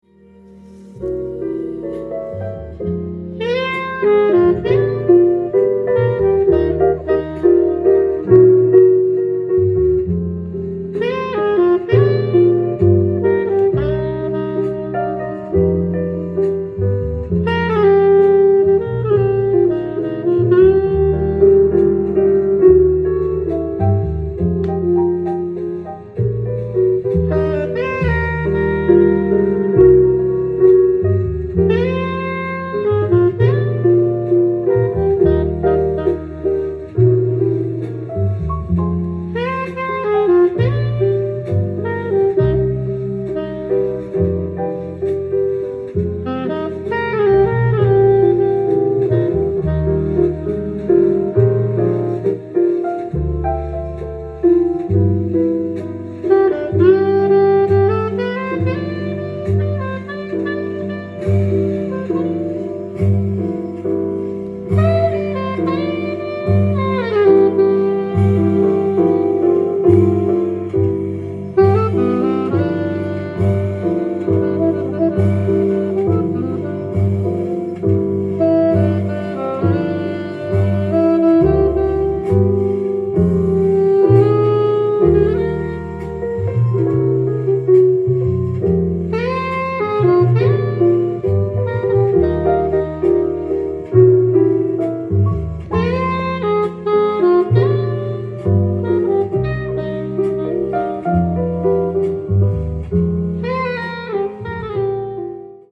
店頭で録音した音源の為、多少の外部音や音質の悪さはございますが、サンプルとしてご視聴ください。